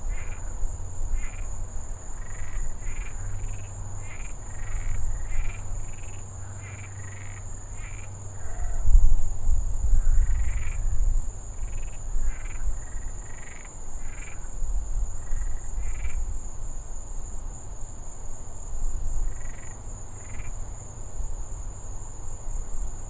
令人毛骨悚然的恐怖 " 青蛙2
描述：另一只青蛙在鸣叫。
标签： 青蛙
声道立体声